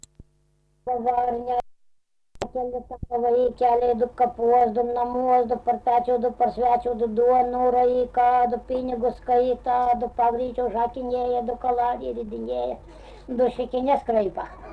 daina, vaikų
Rageliai
vokalinis